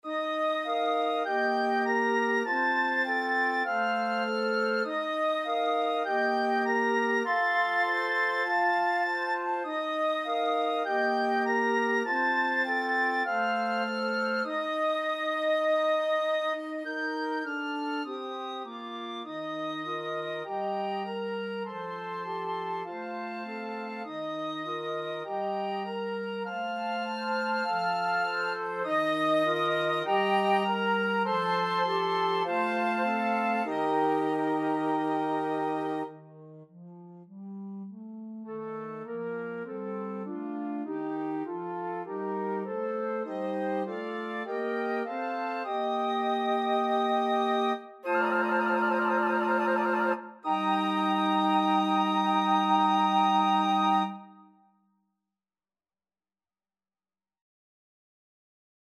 Piccolo / Flute 1 / Flute 2 / Alto Flute / Bass Flute